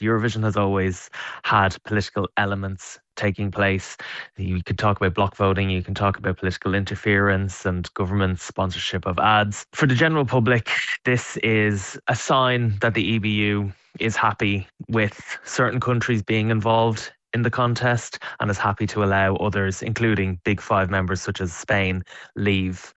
Eurovision expert